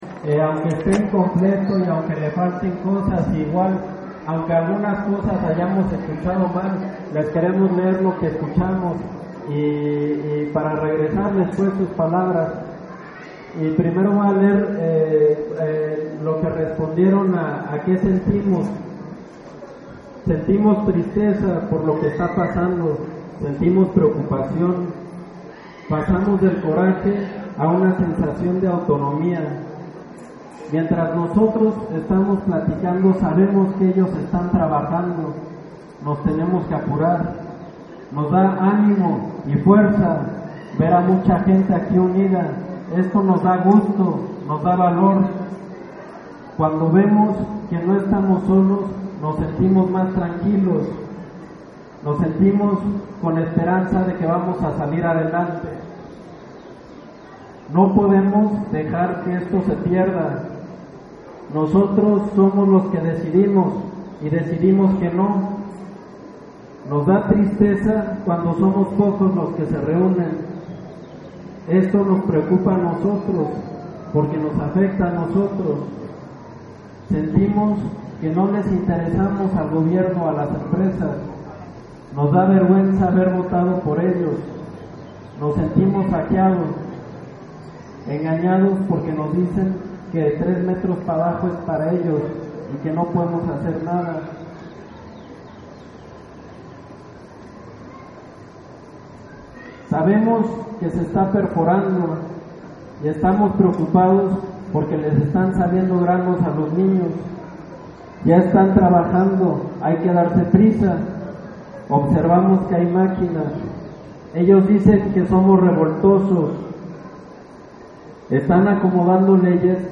Este 22 de junio se llevó a cabo el foro Proyectos de muerte en la sierra norte de Puebla y su impacto en los Derechos Humanos, en la cancha municipal de Ixtacamaxtitlán en la Sierra Norte de Puebla (SNP), al cual acudieron más de mil personas, habitantes y autoridades de más de 20 comunidades de esa zona.
Recopilación de opiniones de los grupos de trabajo